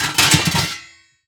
metal_lid_movement_impact_09.wav